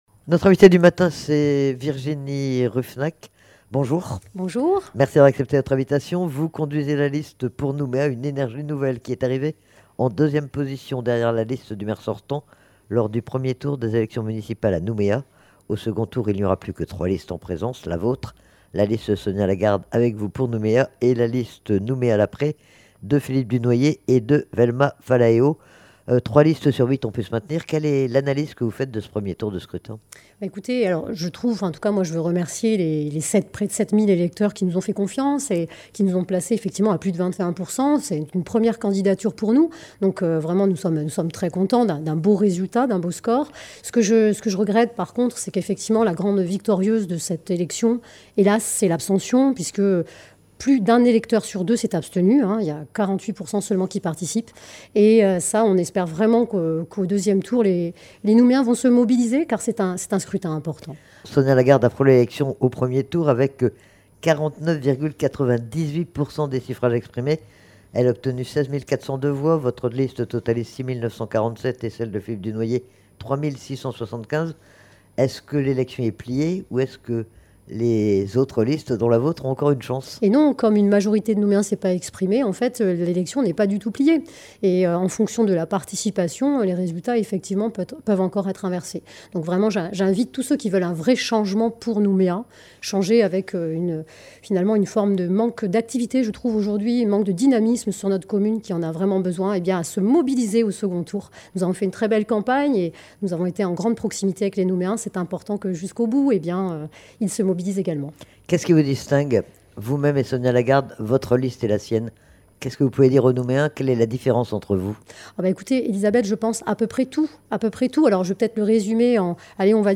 A 7h30, c’est Virginie Ruffenach, tête de liste Pour Nouméa, une énergie nouvelle, qui est notre invitée.